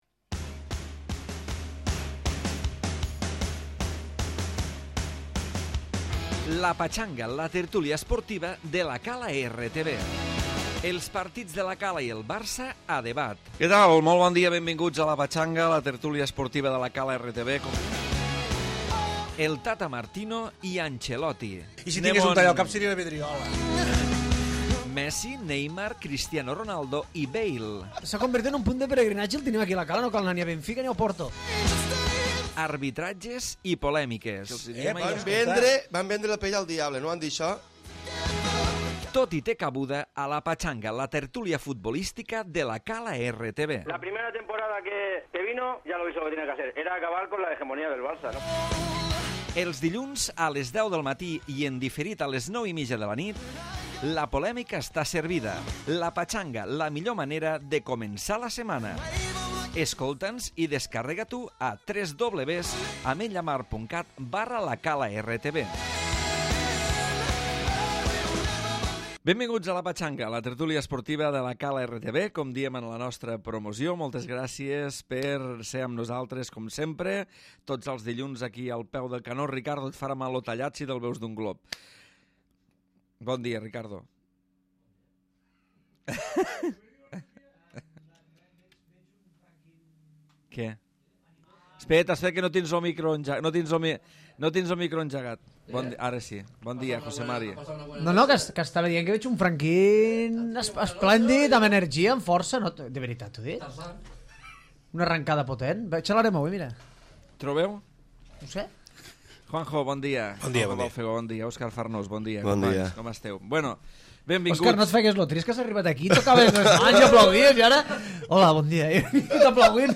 Programa tertúlia centrat en l'àmbit futbolístic que polemitza sobre les situacions del barça i Madrid, amb una part dedicada a la jornada del 1er equip de La Cala.